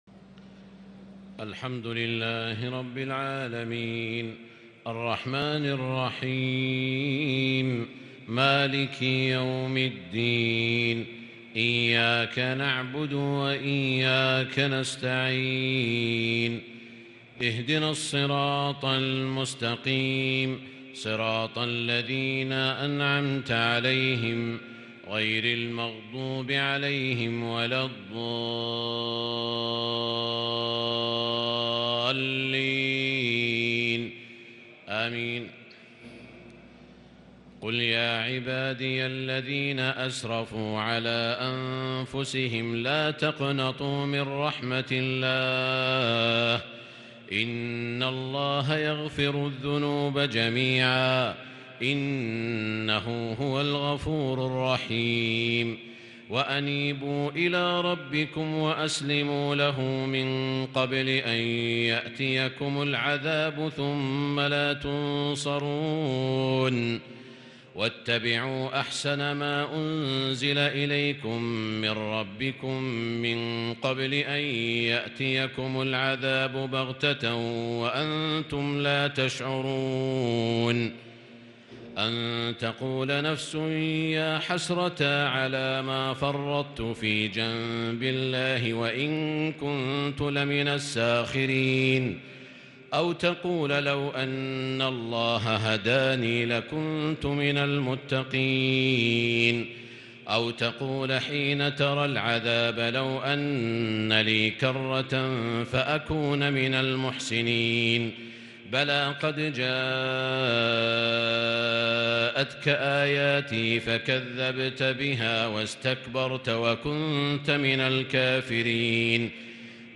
صلاة التراويح | ليلة 26 رمضان 1442 سورة الزمر 53 _ غافر 1-55 | taraweeh prayer The 26th night of Ramadan 1442H | from surah AzZumar and Al-Ghaafir > تراويح الحرم المكي عام 1442 🕋 > التراويح - تلاوات الحرمين